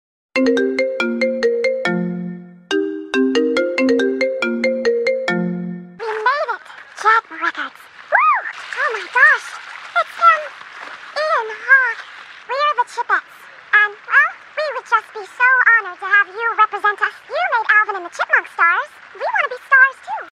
📌 Disclaimer: This is a fun fake call and not affiliated with any official character or franchise.